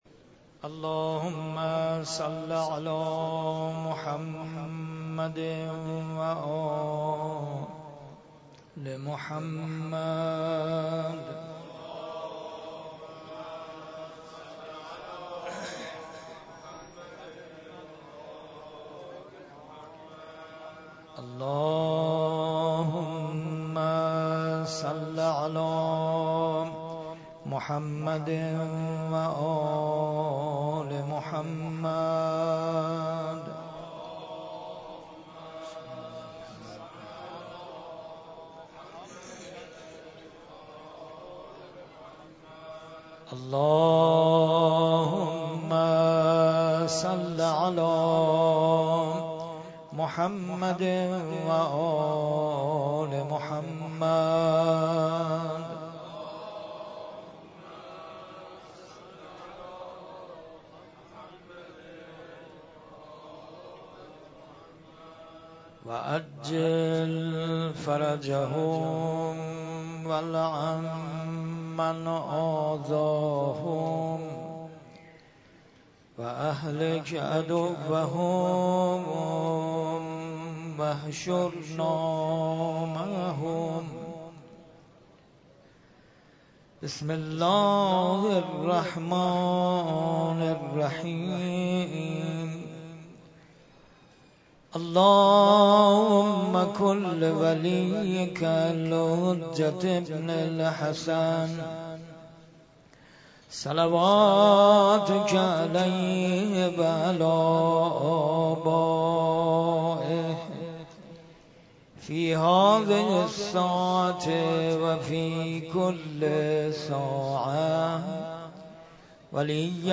قرائت دعای ابوحمزه (قسمت چهارم) ، روضه امام حسن مجتبی (علیه السلام)